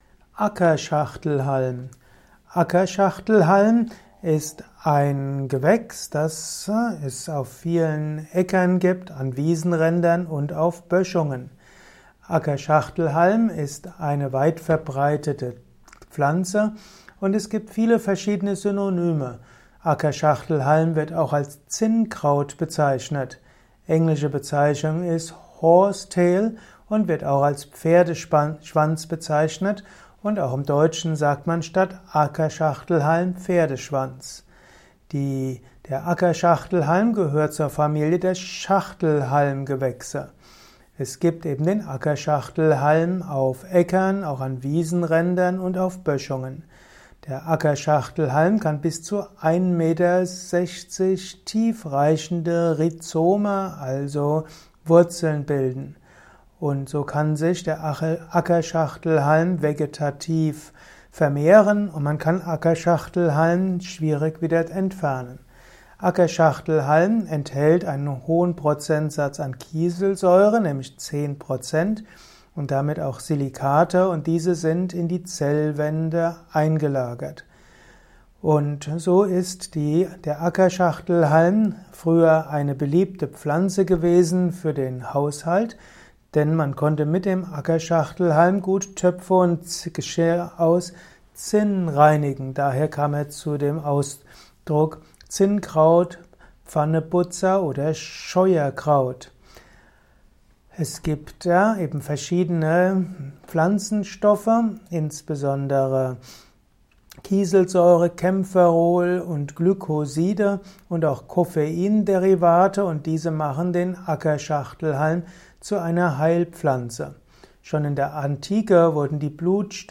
Ackerschachtelhalm - Simple und komplexe Gedankengänge zum Thema Ackerschachtelhalm in diesem kleinen Improvisations-Vortrag. Erfahre einiges rund um den Ackerschachtelhalm unter Gesichtspunkten des klassischen Yoga und Ayurveda aus.